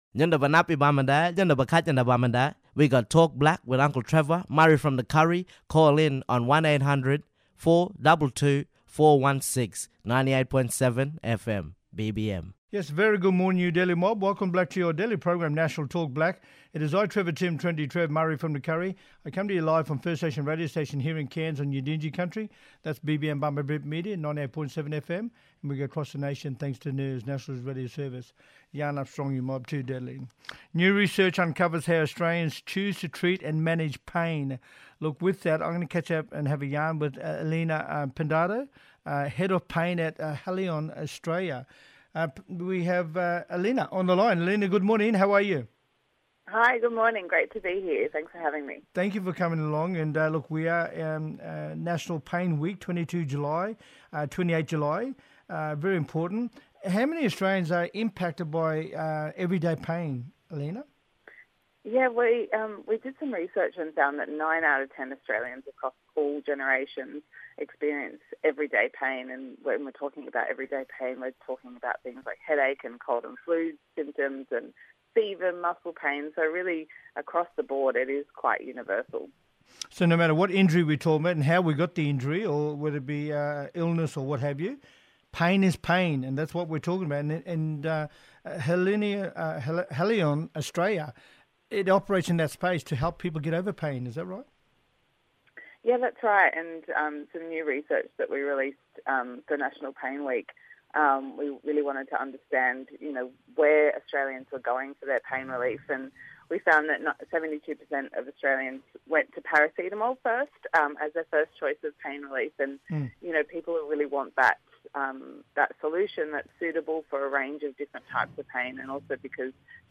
On todays National Talk Black via NIRS – National Indigenous Radio Service we have: